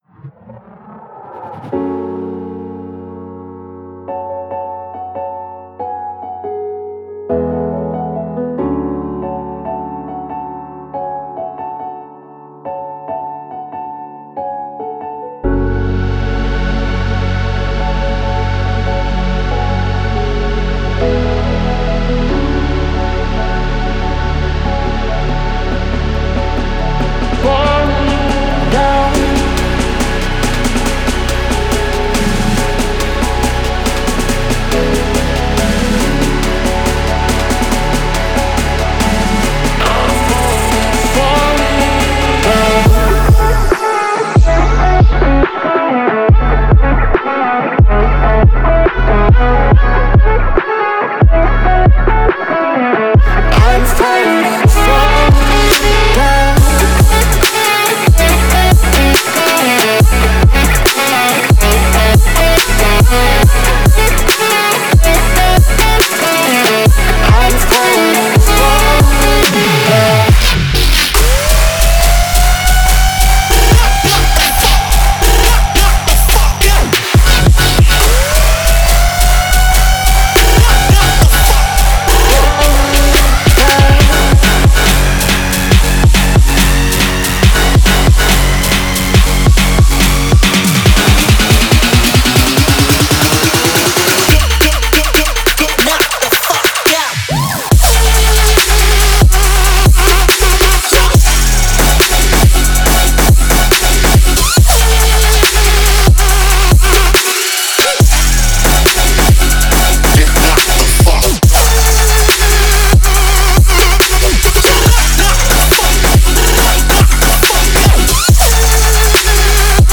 энергичная EDM-трек